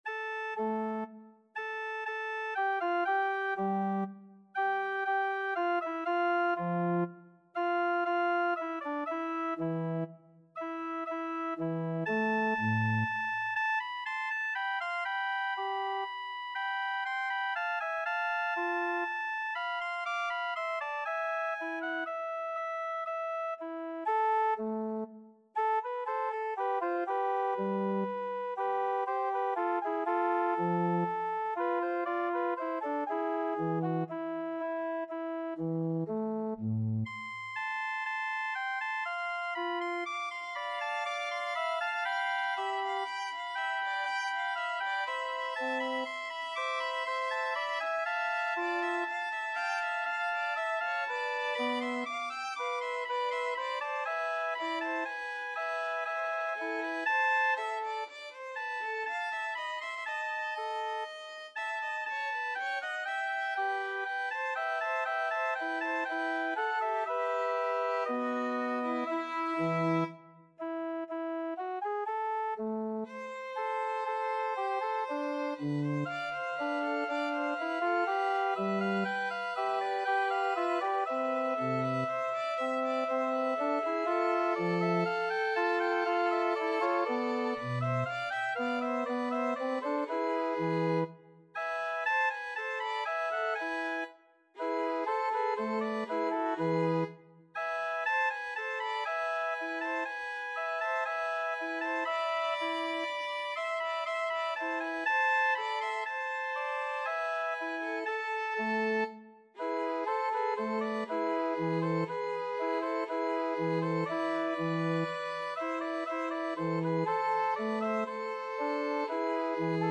Free Sheet music for Violin
A minor (Sounding Pitch) (View more A minor Music for Violin )
Adagio
3/4 (View more 3/4 Music)
Classical (View more Classical Violin Music)
vivaldi_arr_bach_bwv593_2nd_VLN.mp3